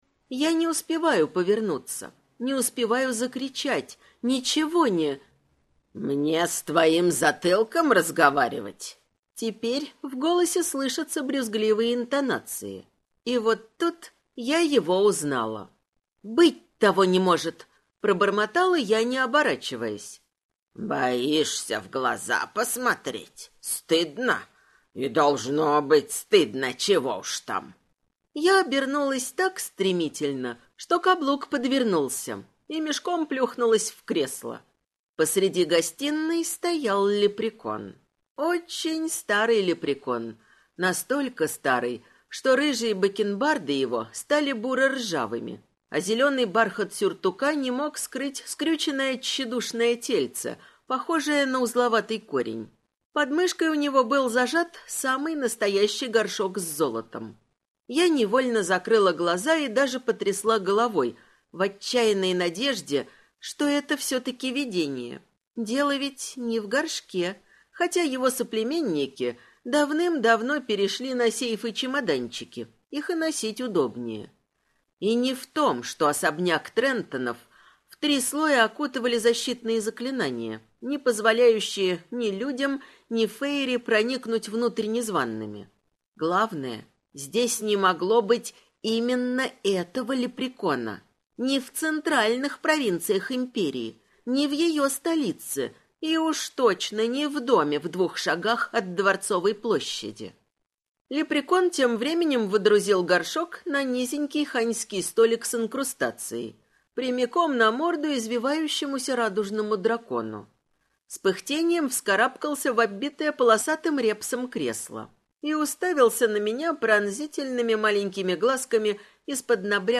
Аудиокнига Леди-горничная возвращается. Книга 1 | Библиотека аудиокниг